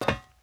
metalFootStep05.wav